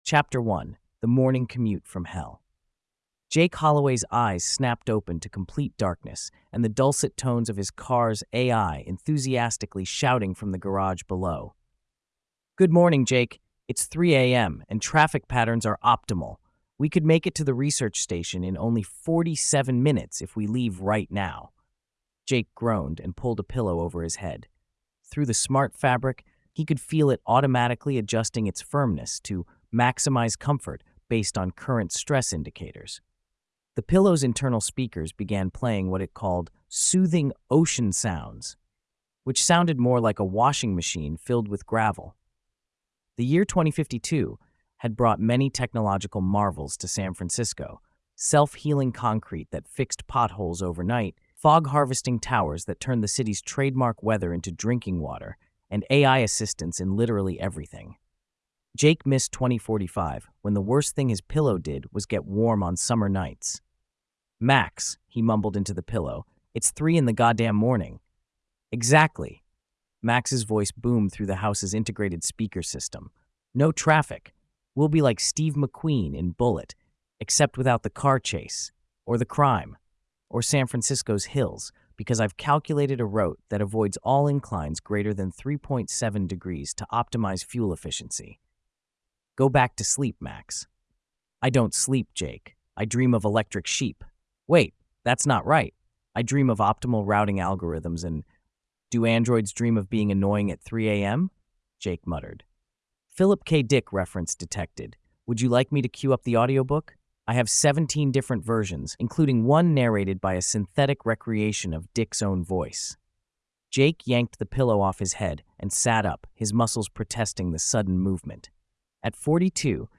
Listen to the first chapter narrated with professional voice synthesis